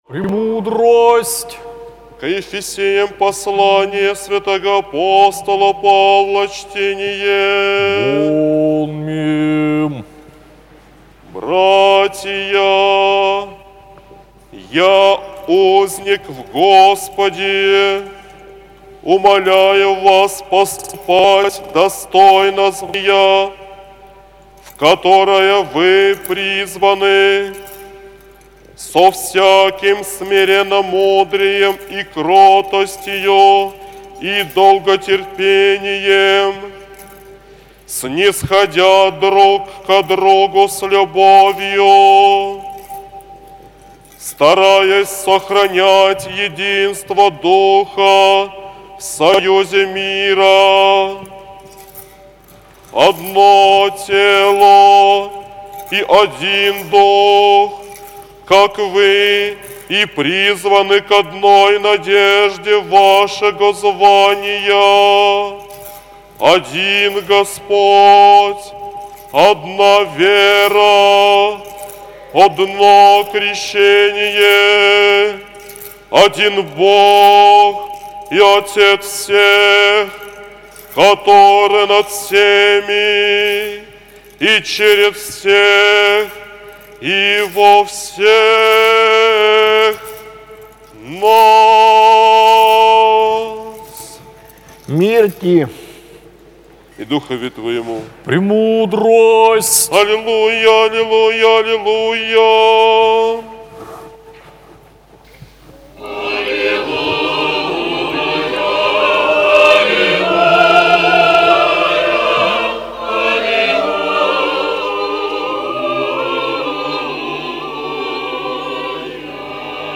АПОСТОЛЬСКОЕ ЧТЕНИЕ